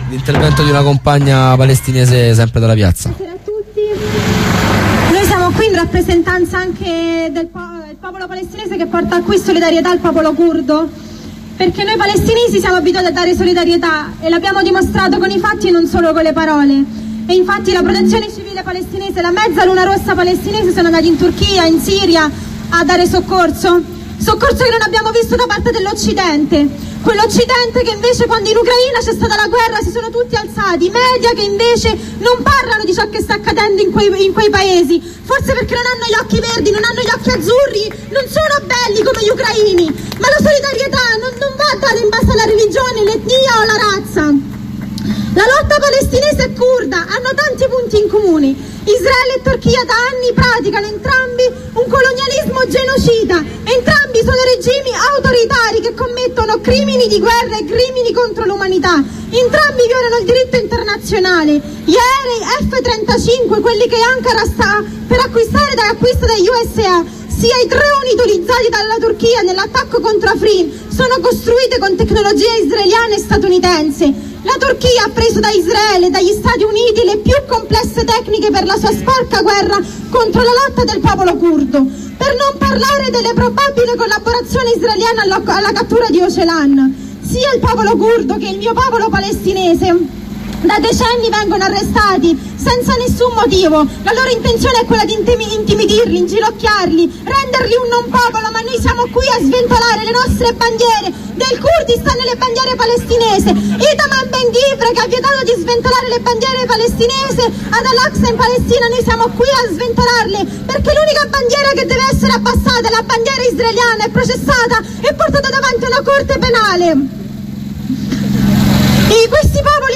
Libertà per Ocalan - interventi dal corteo
Serie di corrispondenze e contributi dalla manifestazione nazionale di Roma per la liberazione di Ocalan
corteo ocalan intervento compagna palestinese.ogg